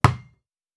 Foley Sports / Basketball / Generic Bounce Intense.wav
Generic Bounce Intense.wav